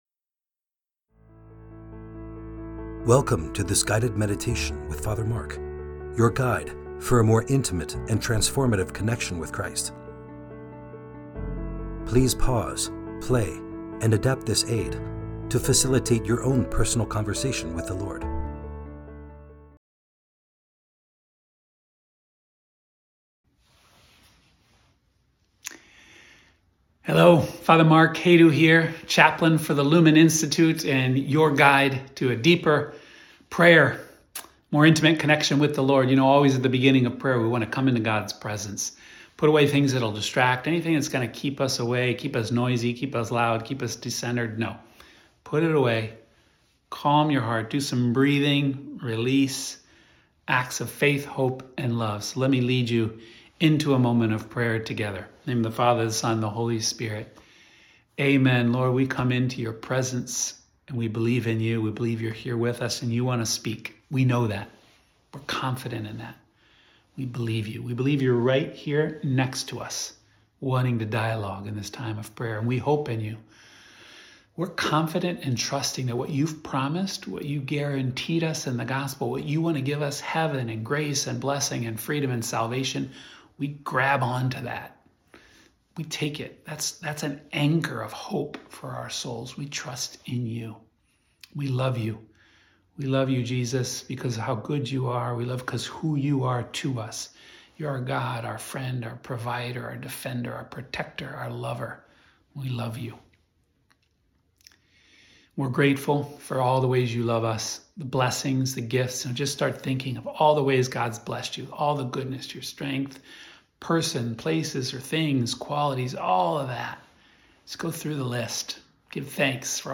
Friday Meditation